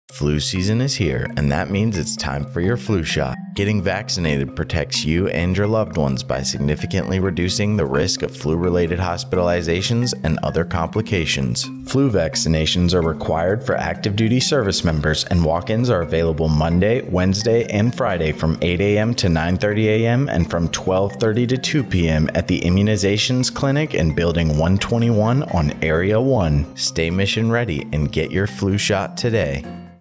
An American Forces Network Aviano radio spot highlighting the flu vaccinations provided by the 31st Medical Group at Aviano Air Base, Italy, Nov. 21, 2025. The 31st MDG provides patient-focused medical care and offers vaccinations to service members and their families to ensure the readiness of the 31st Fighter Wing.